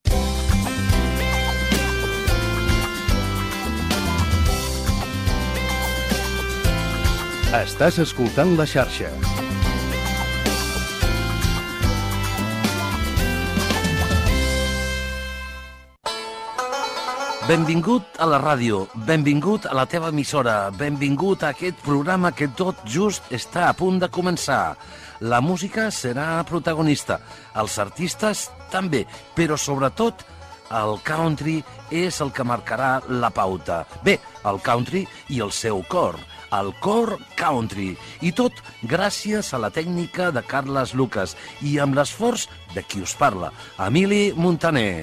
Indicatiu de la ràdio Inici i crèdits del programa.
Musical